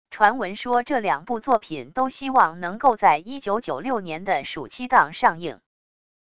传闻说这两部作品都希望能够在1996年的暑期档上映wav音频生成系统WAV Audio Player